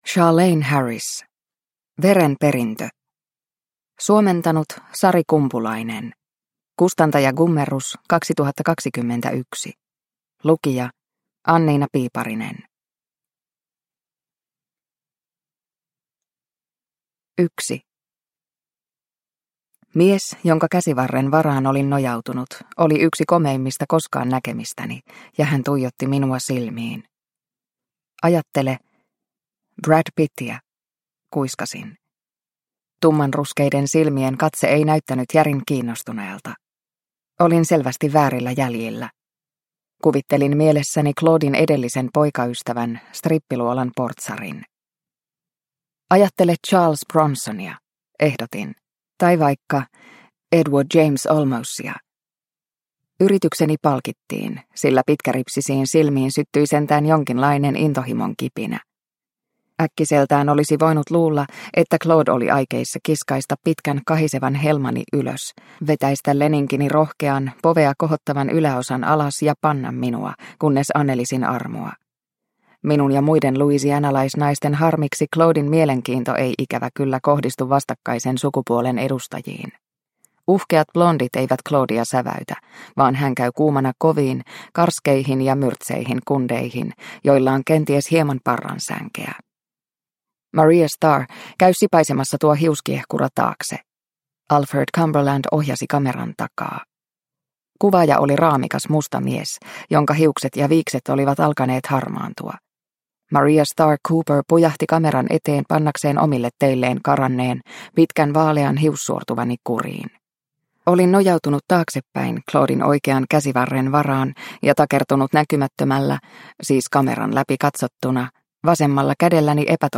Veren perintö – Ljudbok – Laddas ner